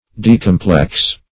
Search Result for " decomplex" : The Collaborative International Dictionary of English v.0.48: Decomplex \De"com*plex`\, a. [Pref. de- (intens.)